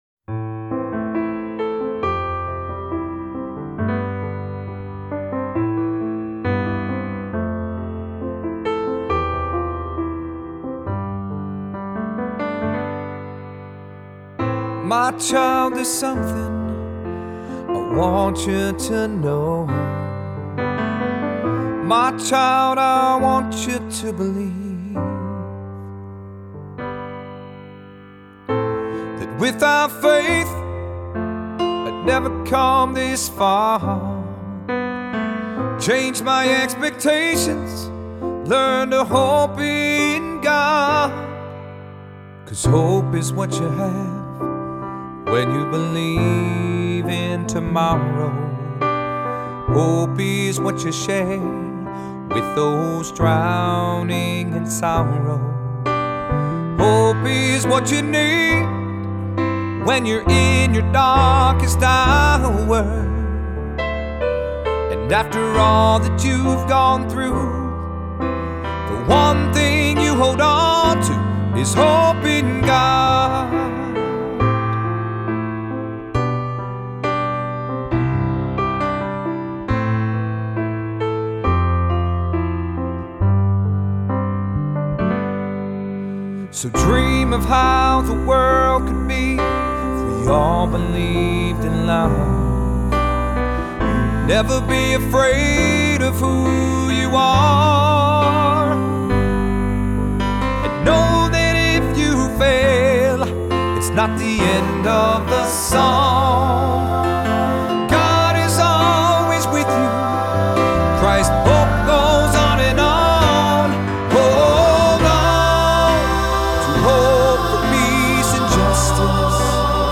Voicing: SATB,Soloist or Soloists, assembly